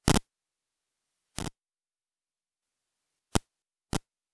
Zigbee transmission: device power command.